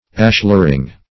Search Result for " ashlering" : The Collaborative International Dictionary of English v.0.48: Ashlaring \Ash"lar*ing\, Ashlering \Ash"ler*ing\, n. 1. The act of bedding ashlar in mortar.